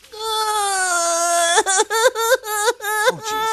Movies
Mort Crying